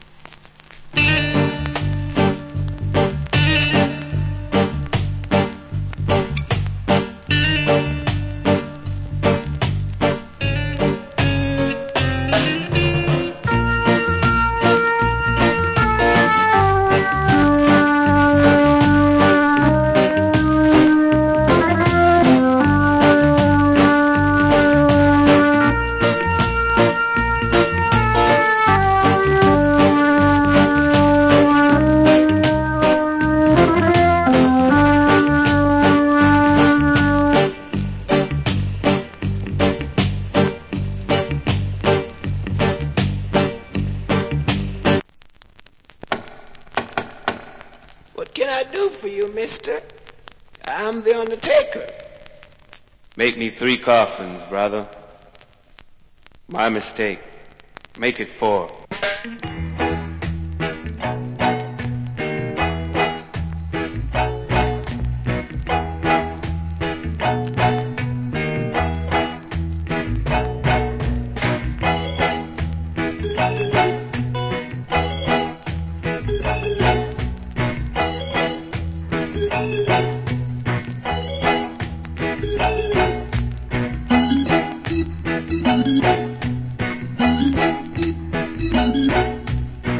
Killer Rock Steady Inst.